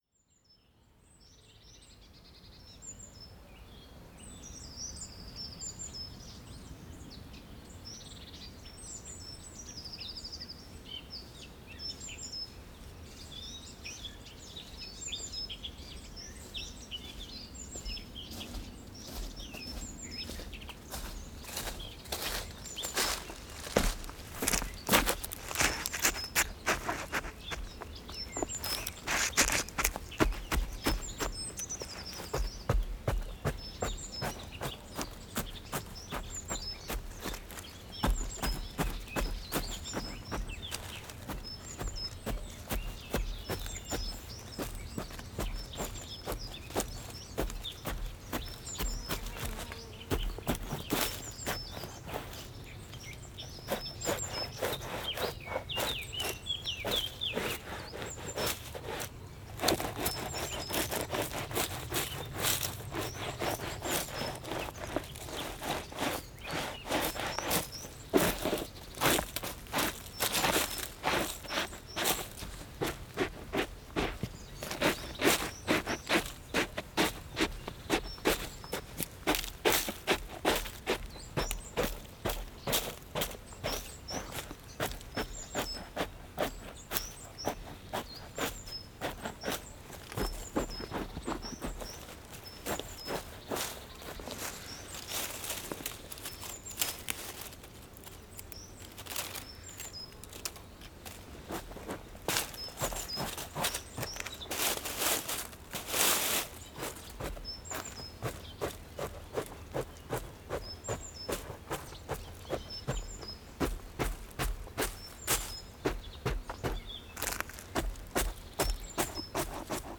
Marcar passo frente a carvalho no lugar de Azival em Cepões, Cepões a 24 Março 2016.
NODAR.00566 – Barreiros e Cepões: Marcar passo frente a carvalho no lugar de Azival em Cepões